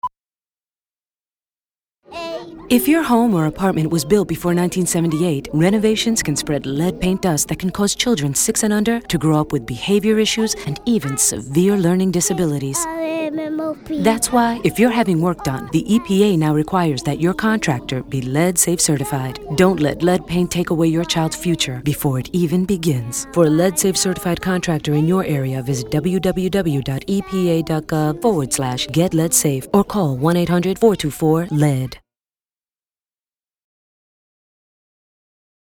Web site contains audio files and transcripts for lead public service announcements in both English and Spanish.